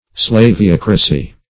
Slaveocracy \Slave*oc"ra*cy\, n.
slaveocracy.mp3